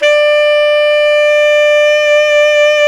SAX TENORB13.wav